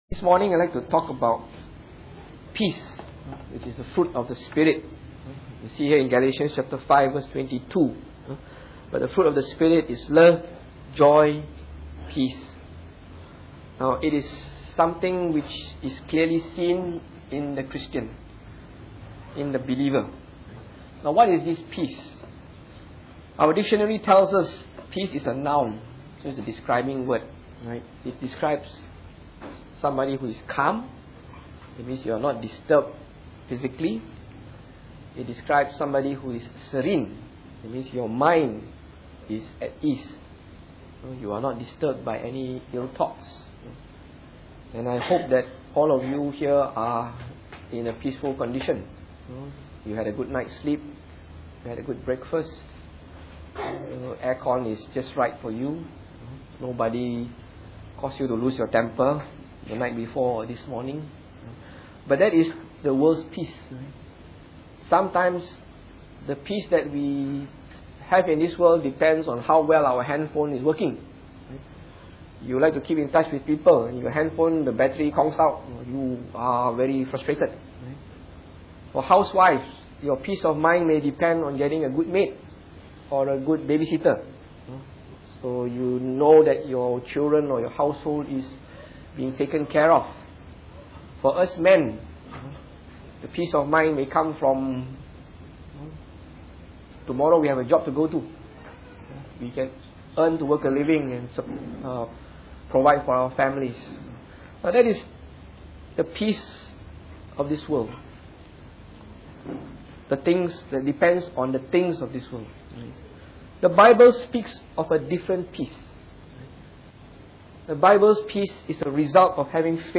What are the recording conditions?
Preached on the 24th of July 2011.